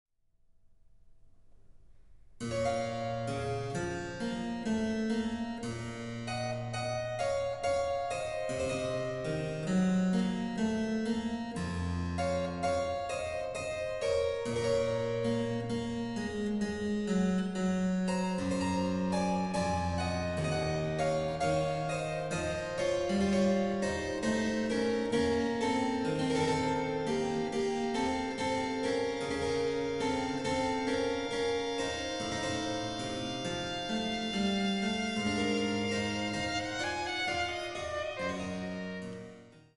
Violine
Cembalo